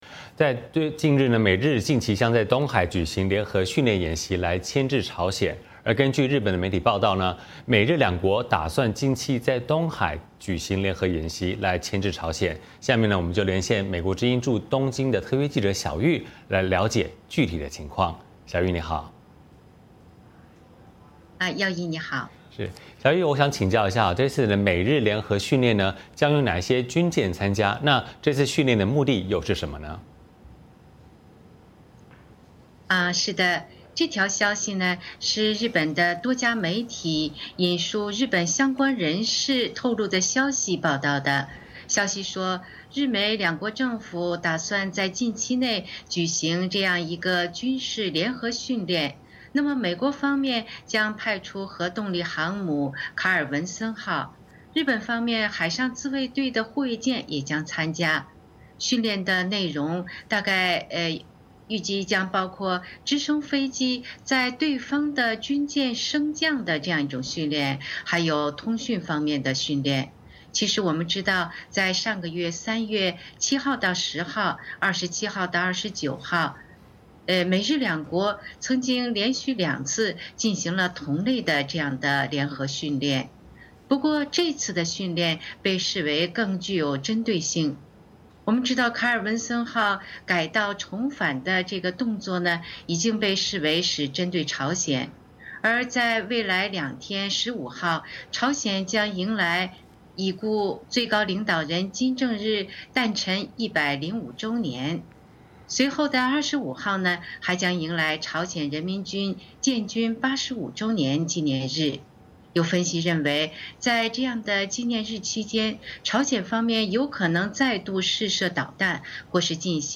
VOA连线：美日近期或将在东海举行联合训练来牵制朝鲜